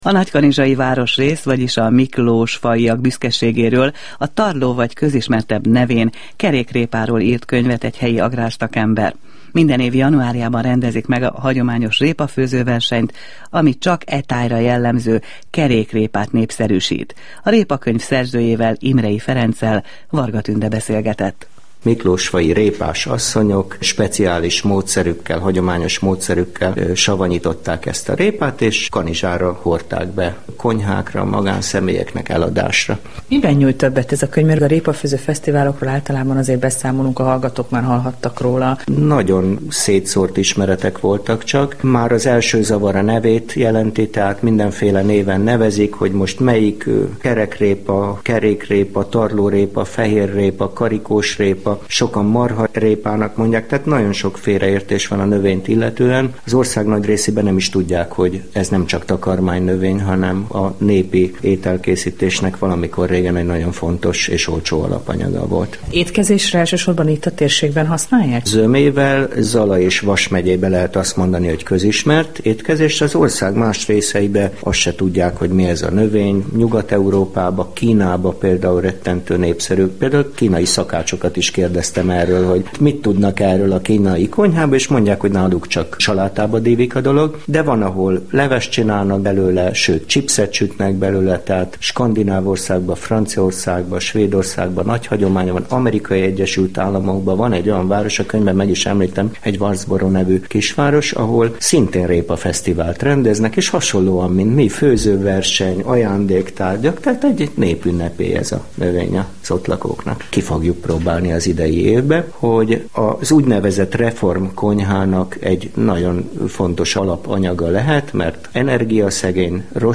kossuth_radio_hajnal_taj_interju.mp3